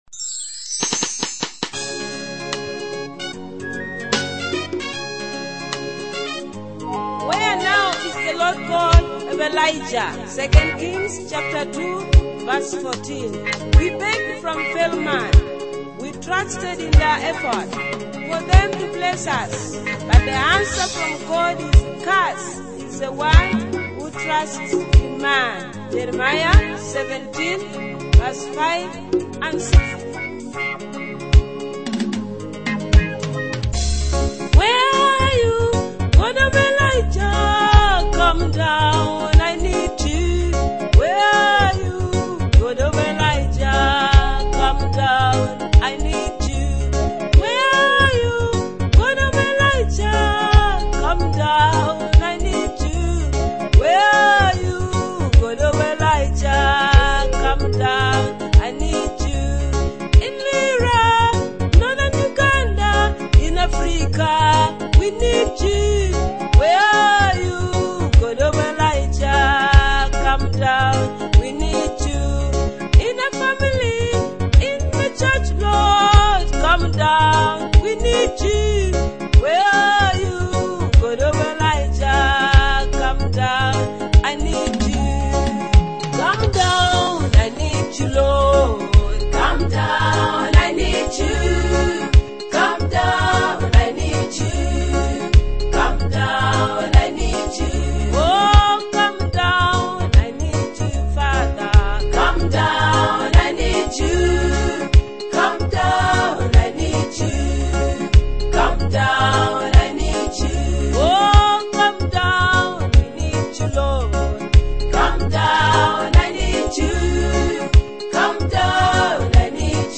gospel worship songs